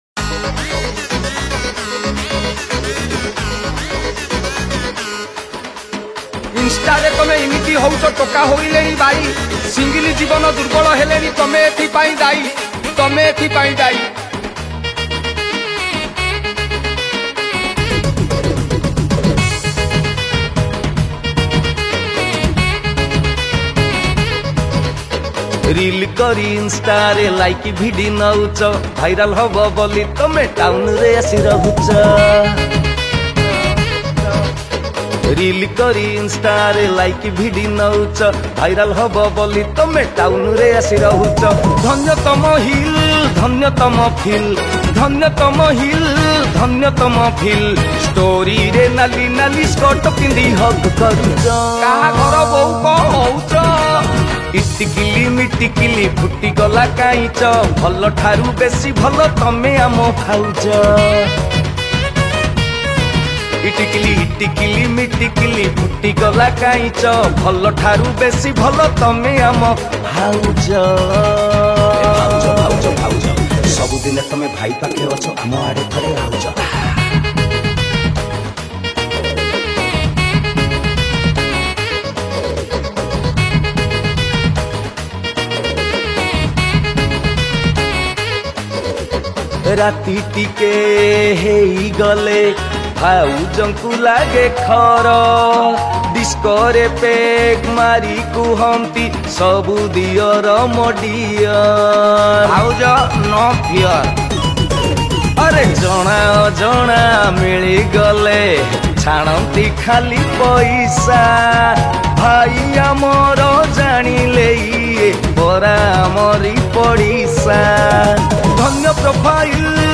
Recorded At : Md Studio Ctc